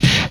player_collision.wav